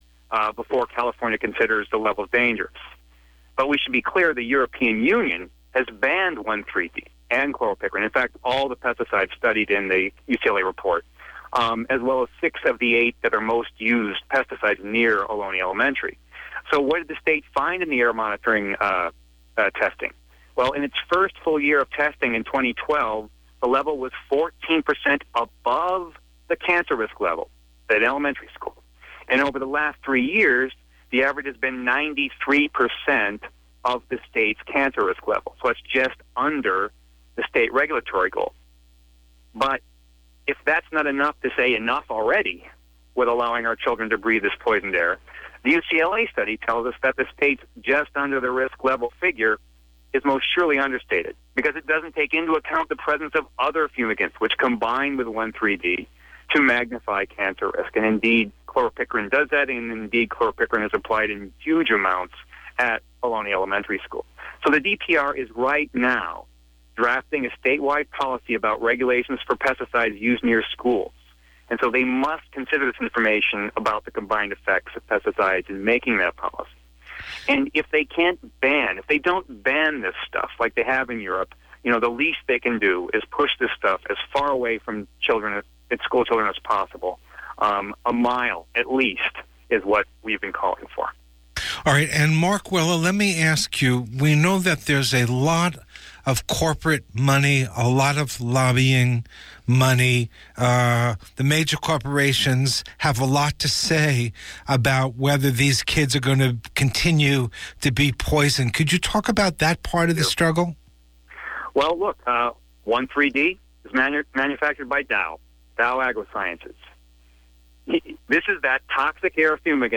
Part 2 of a Talk by Economist Richard Wolff and the Electronic Intifada